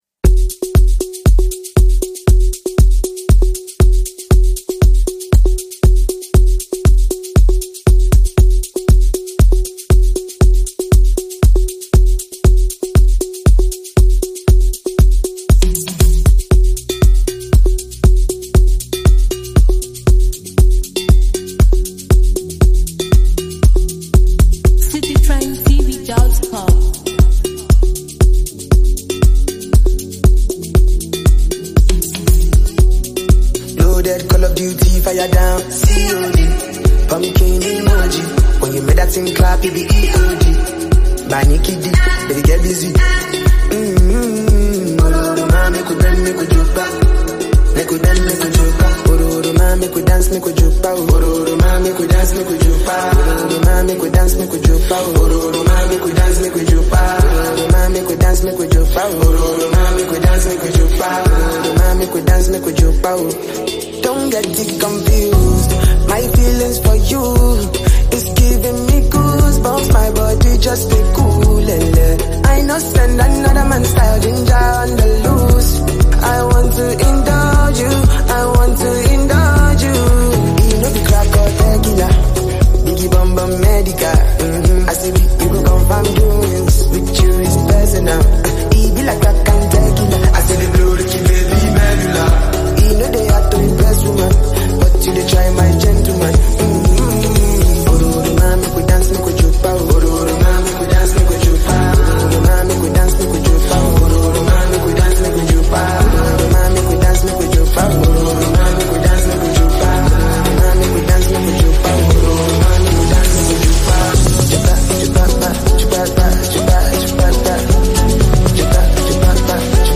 Nigerian Afropop star
infuses the track with new energy and rhythmic flair.
Known for his smooth vocals and melodic delivery